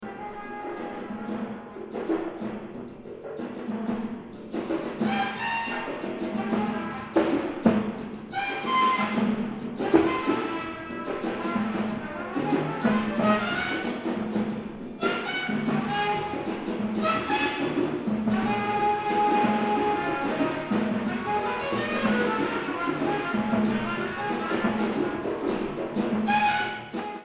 スリリングなアフロビート、リラクシンなジャズファンク、フリージャズ、マンボ